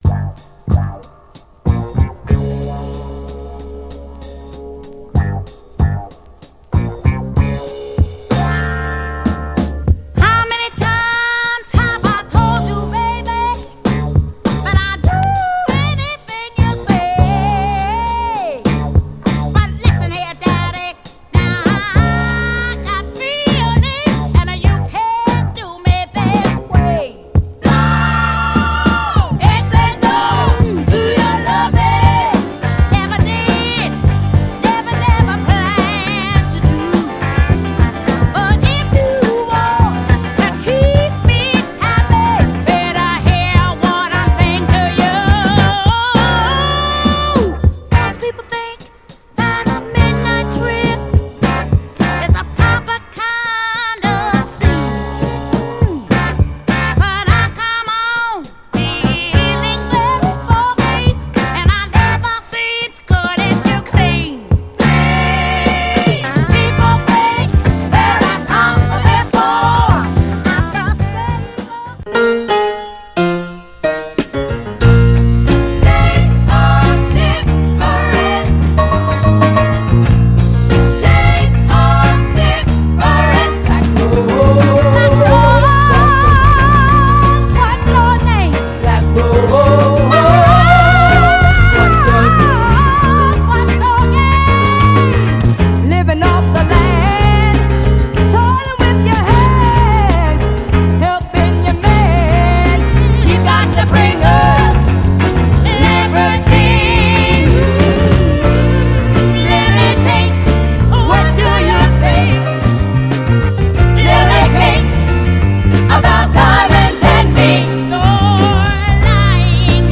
バッドなベースラインが展開がカッコいいファンキー・ソウル
彼女達らしいファンクかつ美メロなゴスペル・ソウル
盤はいくつか表面的なスレありますがプレイは特に問題ありません。
※試聴音源は実際にお送りする商品から録音したものです※